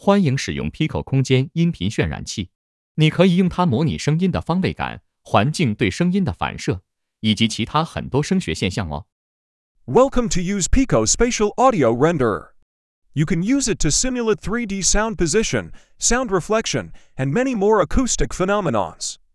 speech_48k.wav